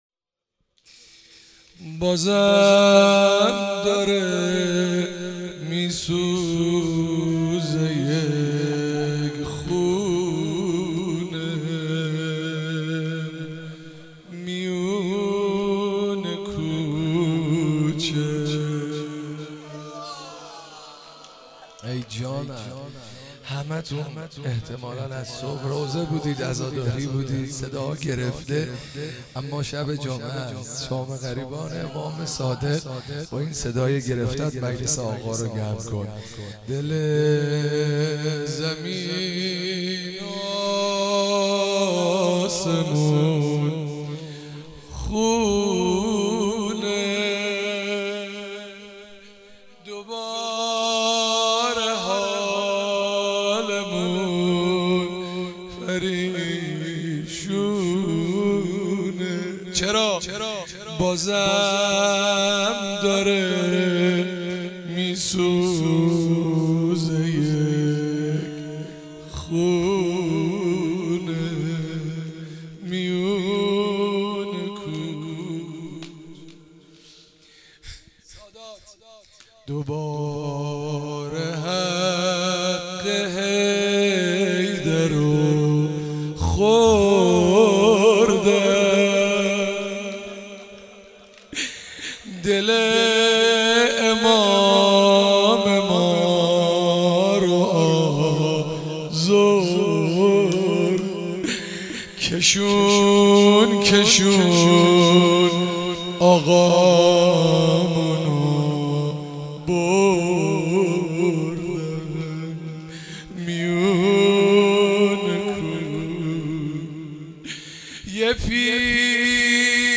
حسینیه حضرت زینب (سلام الله علیها)
روضه امام صادق(ع)
شور